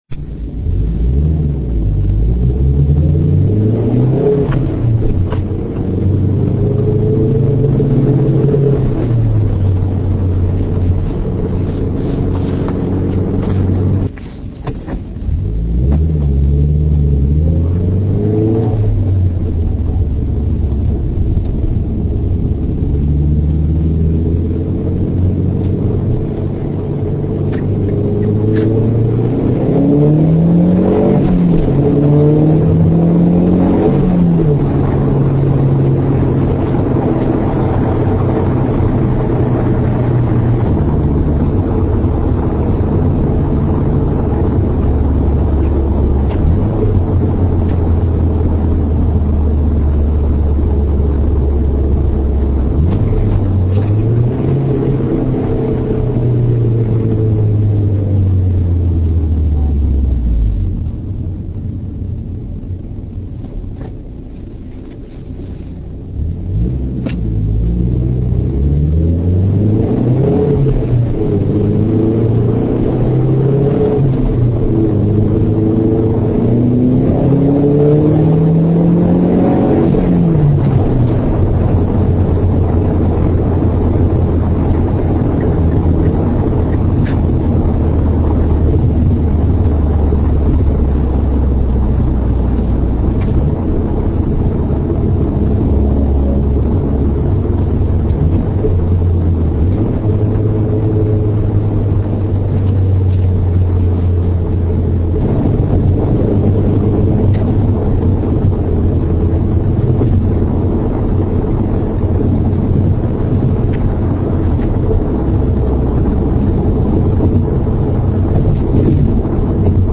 O.K. so here is an mp3 exhaust clip.
It represents normal stop and go driving over a period of about two minutes.
It is attached to a commercial grade Olympus DS-4000 digital recorder. The mike was placed in a fixed position on the right front passenger seat.
The windows were UP, so as to give a sense of what it is like to drive the car daily for commuting on public streets.
But you will notice that you can hear the engine as well as the exhaust and that the exhaust tone is very deep pitched, gurgling, kind of like Flowmasters on a muscle car, and that drone is practically gone.